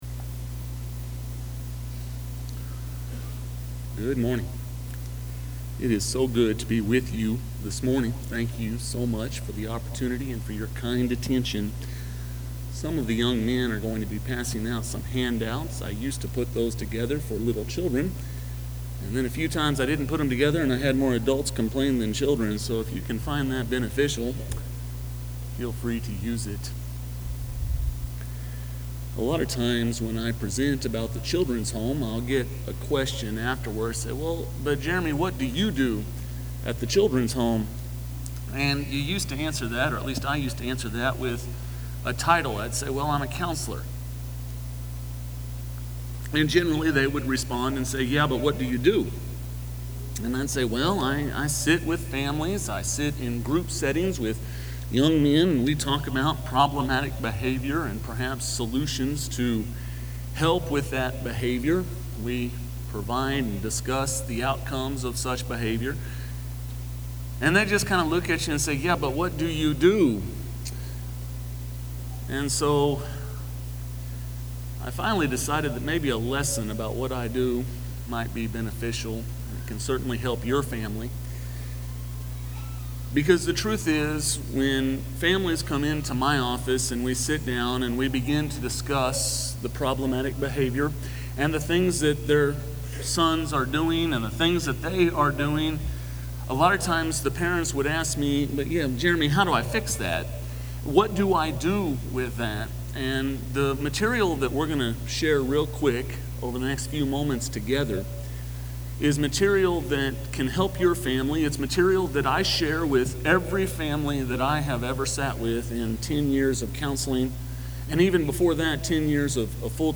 Qualities of Successful Families: Making Families Work – Henderson, TN Church of Christ